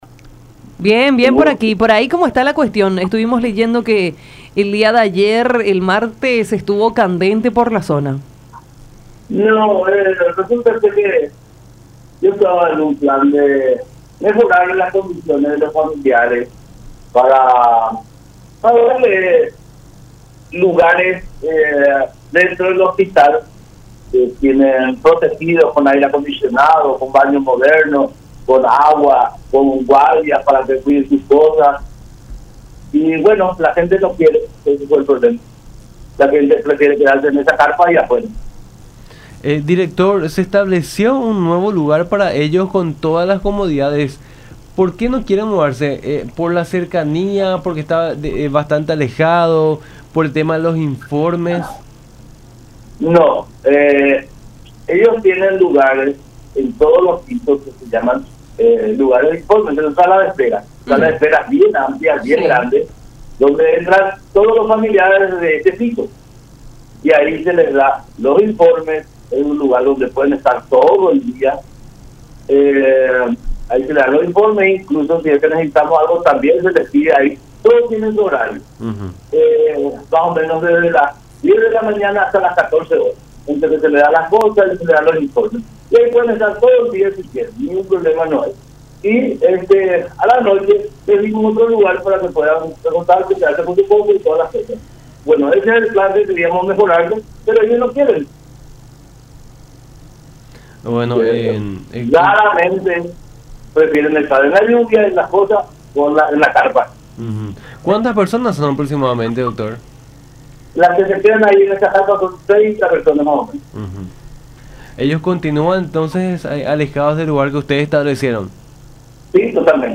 en contacto con Nuestra Mañana por La Unión.